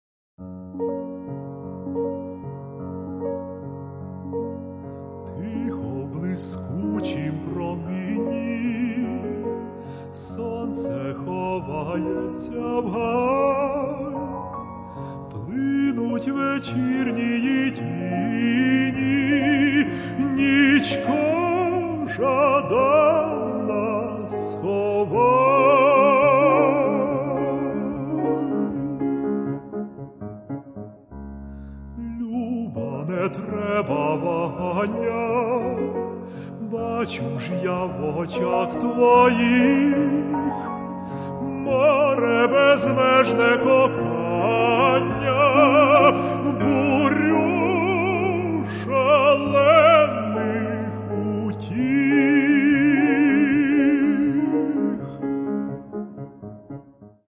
Українські солоспіви